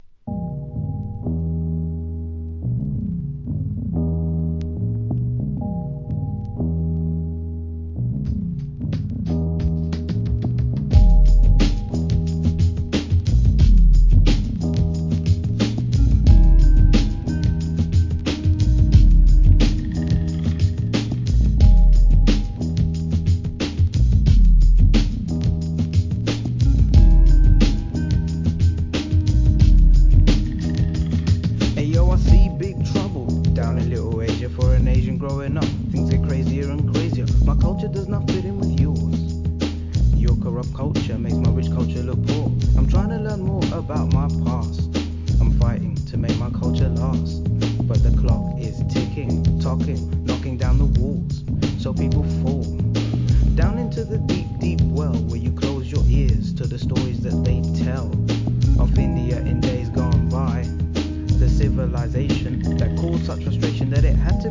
HIP HOP/R&B
'90sのUKアンダーグランド!